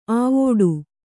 ♪ āvōḍu